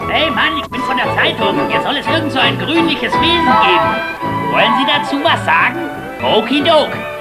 Ich hätte da mal eine Münchner Trick-Synchro von 1989 mit den üblichen Verdächtigen.
Reporter